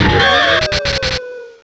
Cri d'Élekable dans Pokémon Diamant et Perle.